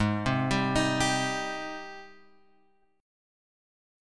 G#M7 chord {4 3 6 5 x 3} chord